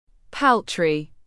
Paltry /ˈpɔːl.tri/